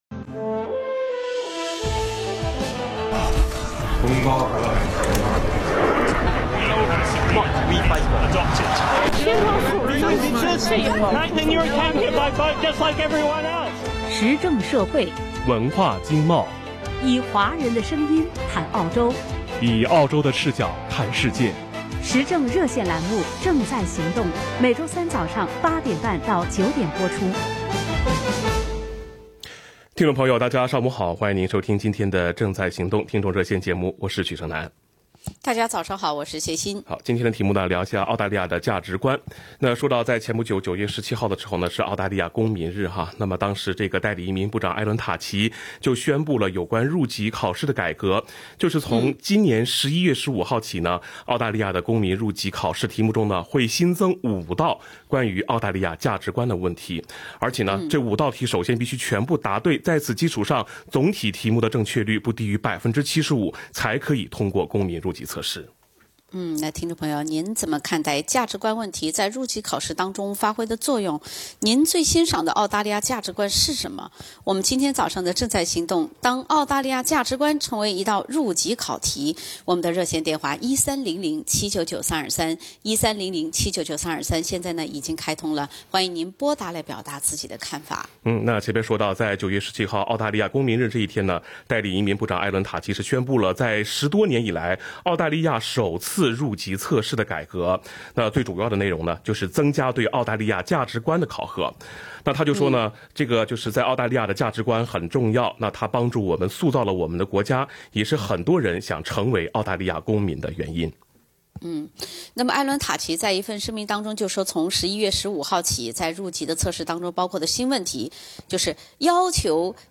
热线听众发言：您最欣赏的澳大利亚价值观是什么？